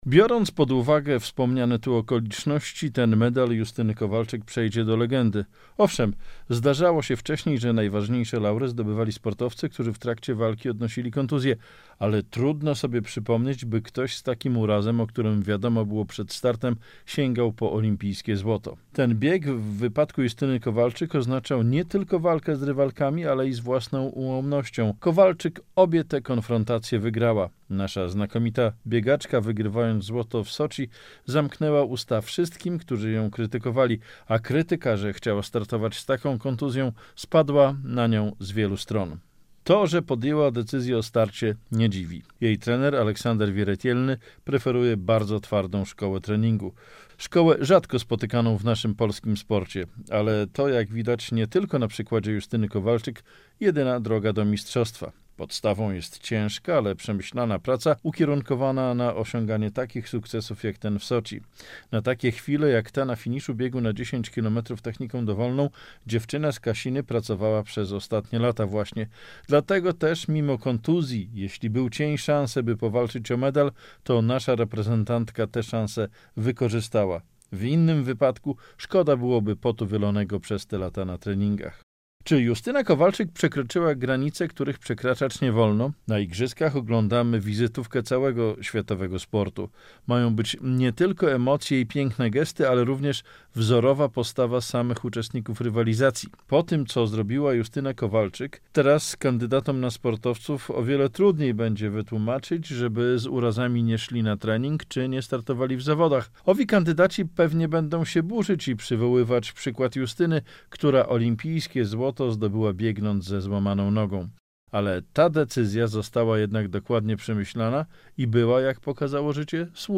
komentarz